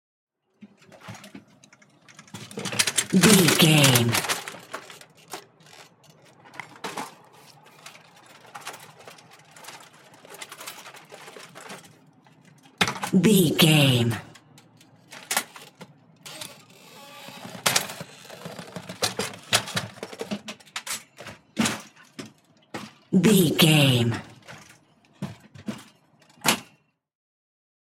Ambulance Stretcher Litter In Out
Sound Effects
chaotic
emergency